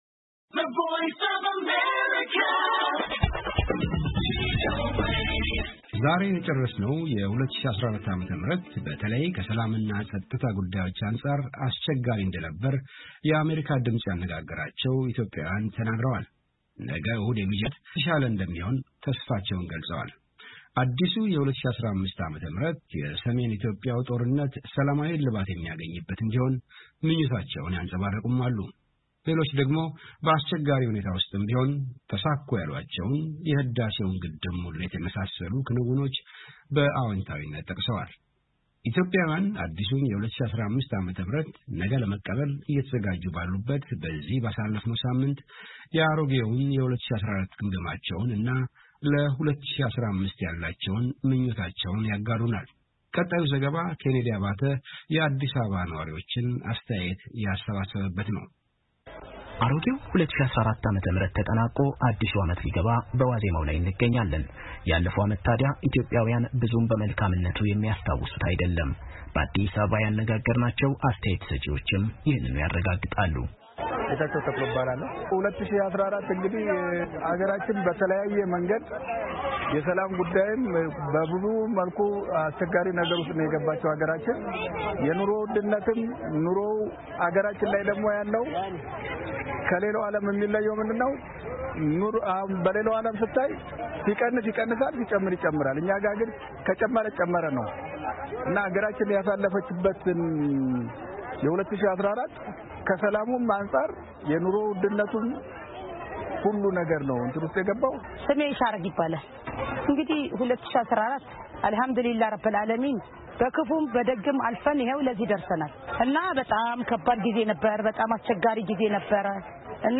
የአዲስ አበባ ነዋሪዎችን አስተያየት ከተያያዘው ፋይል ያድምጡ።